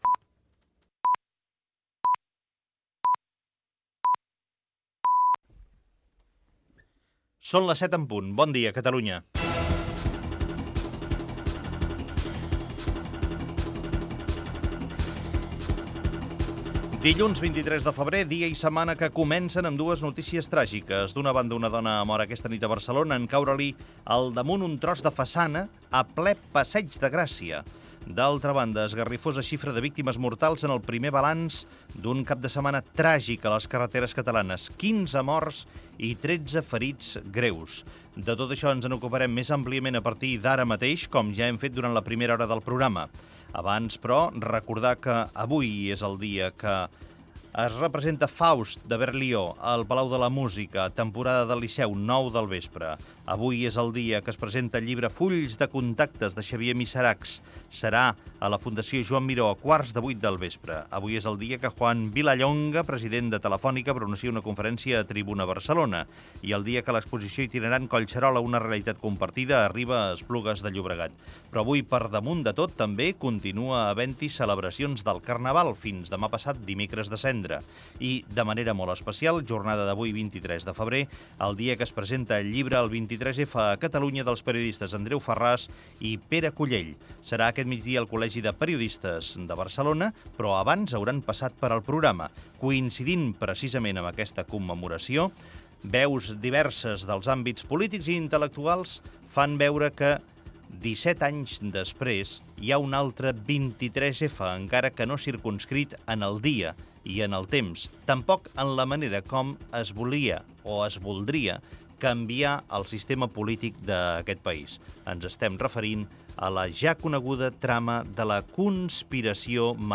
Senyals horaris, hora, titulars de les 07:00 del matí i agenda del dia. Informació meteorològica.
Info-entreteniment
Fragment extret de l'arxiu sonor de COM Ràdio.